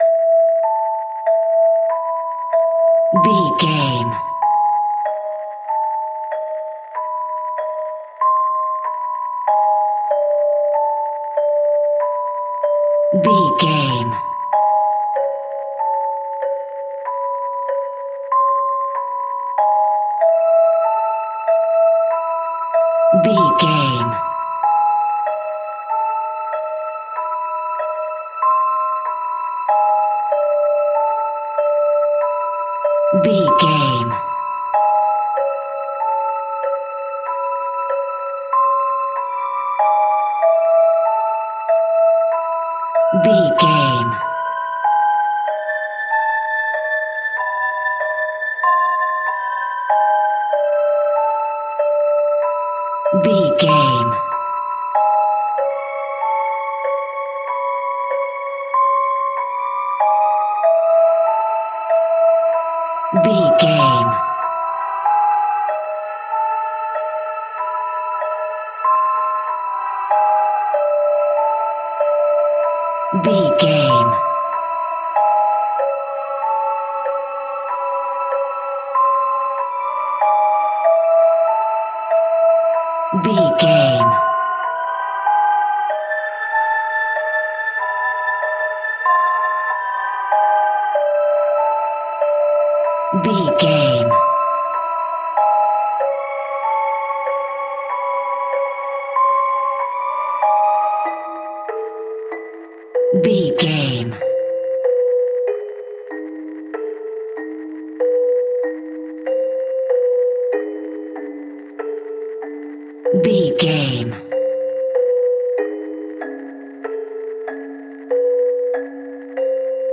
Aeolian/Minor
E♭
scary
ominous
haunting
eerie
strings
percussion
synth
pads